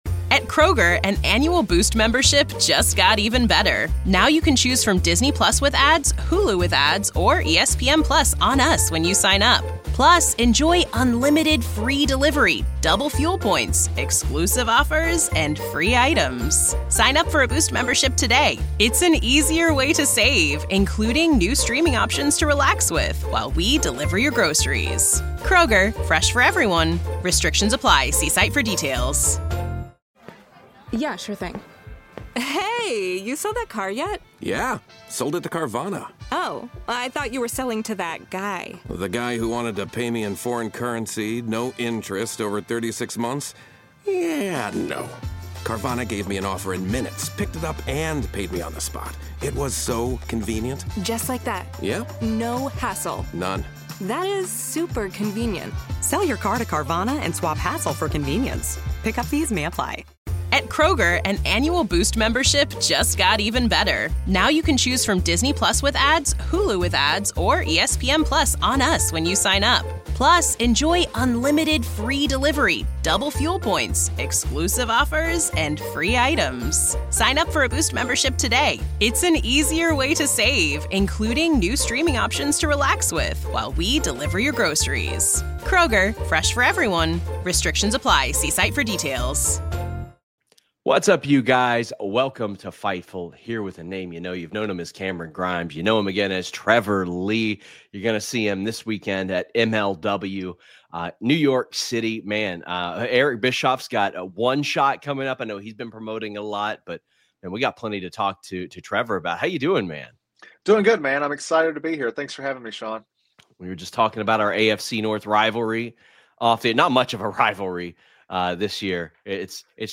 Shoot Interviews